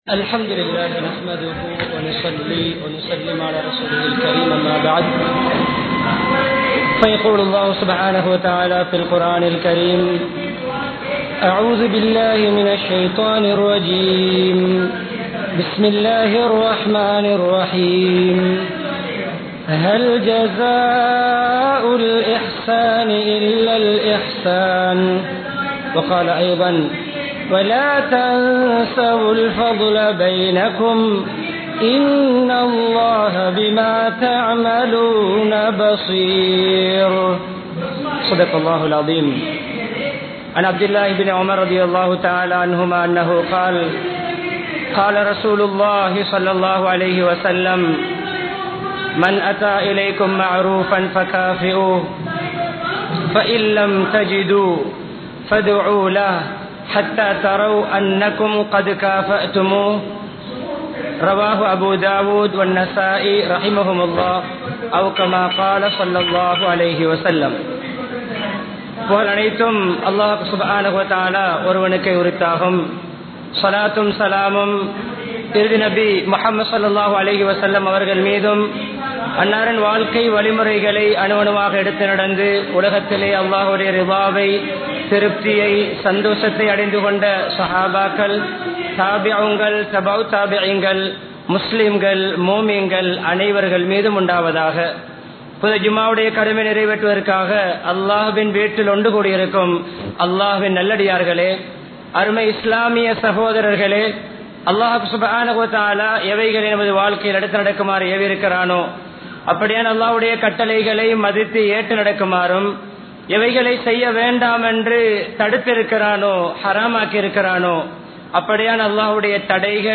நன்றி உள்ளவர்களாக வாழ்வோம் | Audio Bayans | All Ceylon Muslim Youth Community | Addalaichenai
Colombo 03, Kollupitty Jumua Masjith